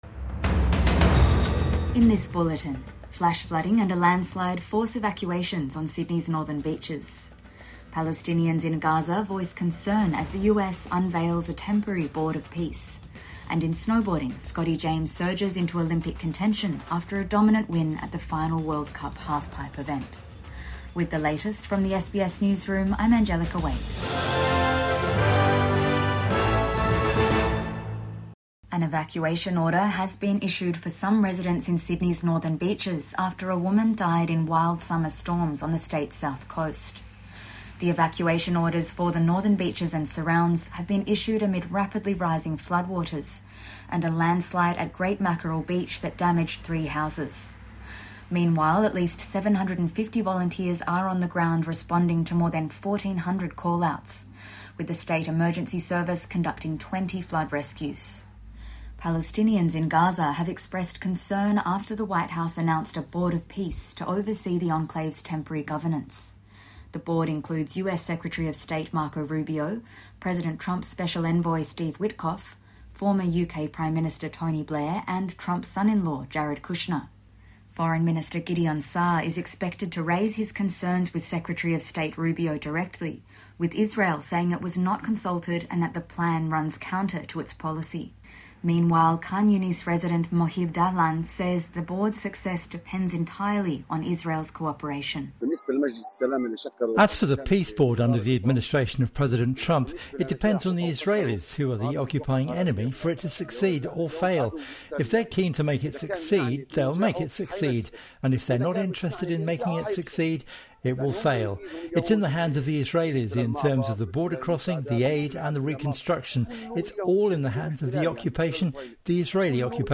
Midday News Bulletin